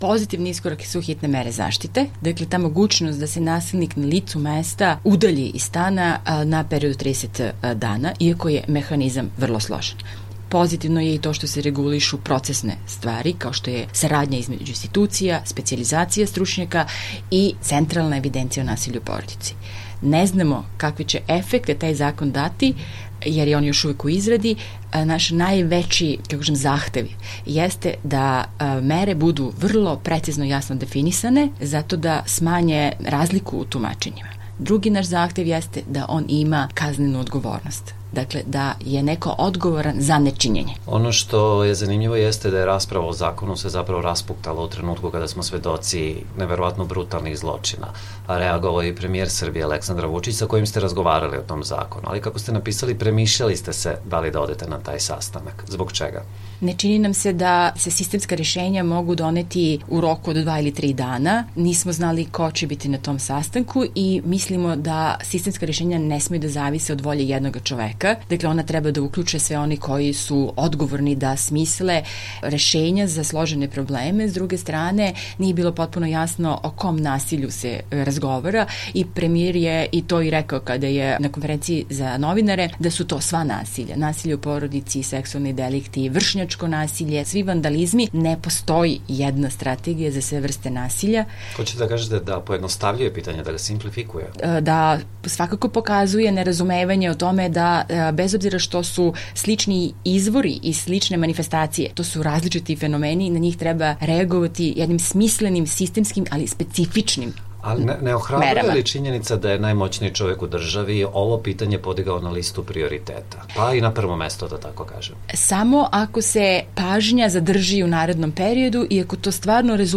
Intervju nedelje